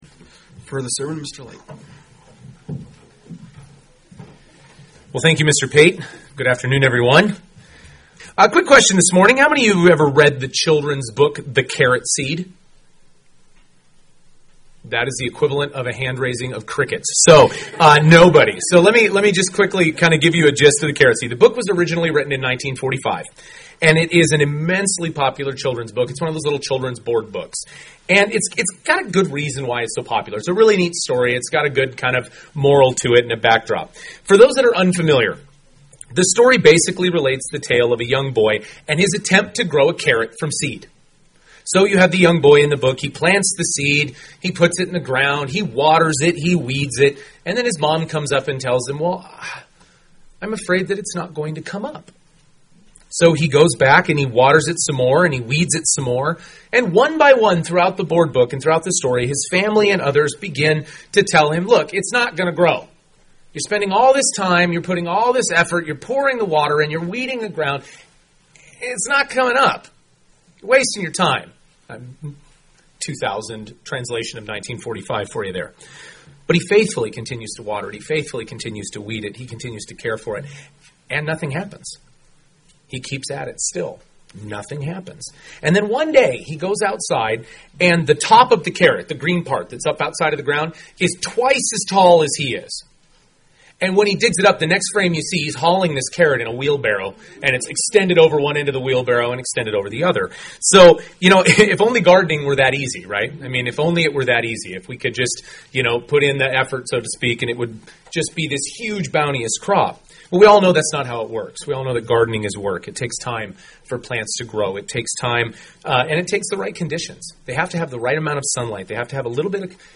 Regular watering UCG Sermon Transcript This transcript was generated by AI and may contain errors.